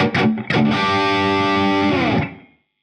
Index of /musicradar/80s-heat-samples/85bpm
AM_HeroGuitar_85-G02.wav